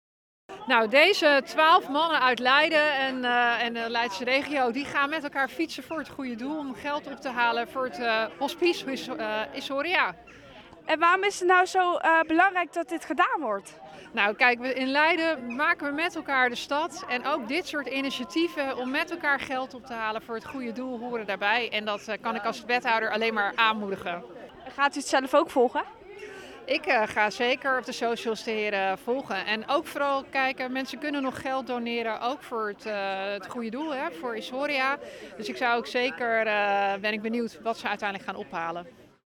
Wethouder Wietske Veltman gaf woensdagmiddag op het Stadhuisplein in Leiden het startschot van een bijzondere fietstocht. Twaalf mannen stapten op hun ‘gewone fiets’ voor een vijfdaagse rit naar Liverpool.
in gesprek met Wietske Veltman over de fietstocht voor Issoria